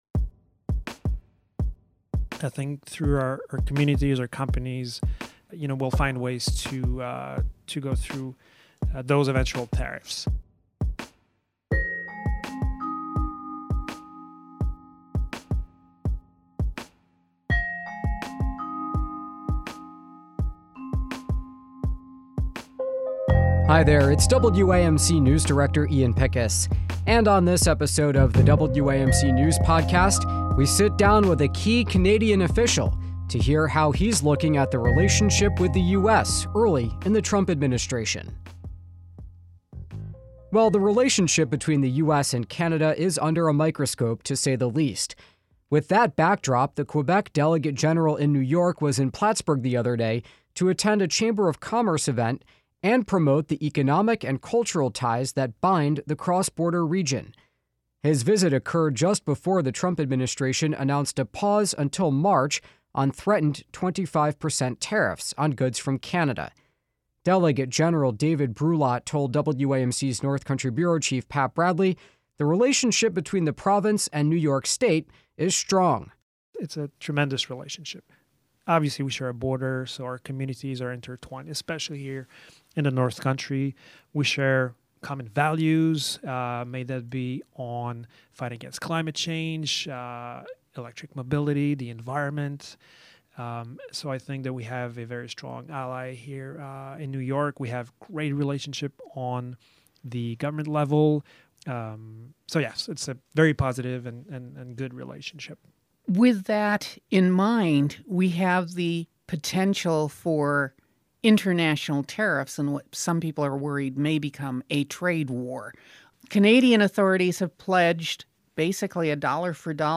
We sit down with Quebec Delegate General in New York David Brulotte at a time when the U.S.-Canada relationship is under a microscope.